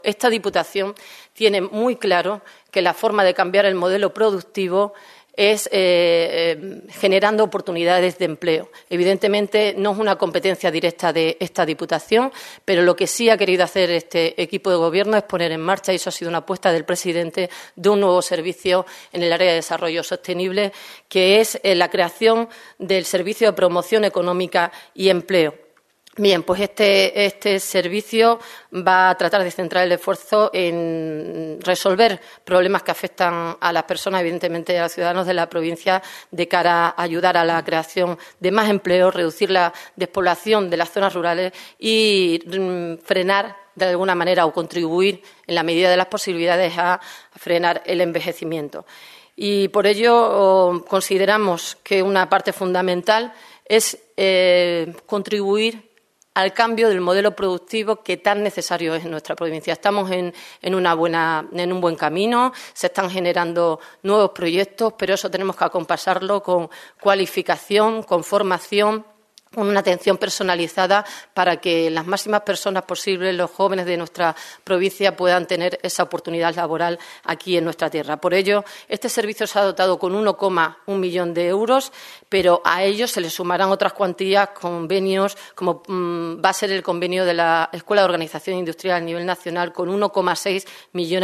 CORTES DE VOZ
Vicepresidenta 1ª_ Esther Gutiérrez Morán_Consejo Empleo